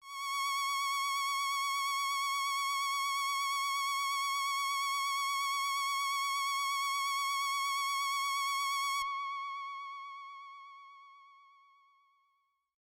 Akai AX80 Spacey Strings " Akai AX80 Spacey Strings C6 (85TAT6)
标签： CSharp6 MIDI音符-85 赤-AX80 合成器 单票据 多重采样
声道立体声